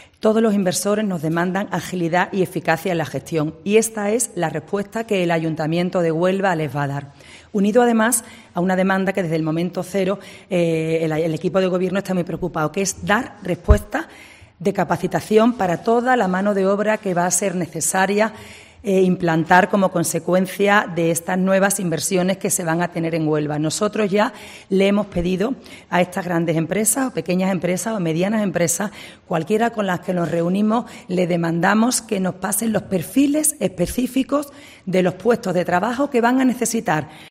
Adela de Mora, teniente Alcalde Empleo, Desarrollo Económico, F.Europeos y Vivienda del Ayuntamiento